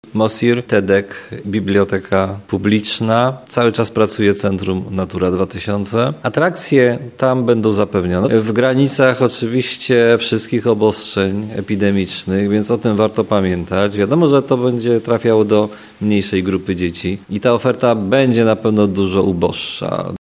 Mówi prezydent Tarnobrzega, Dariusz Bożek.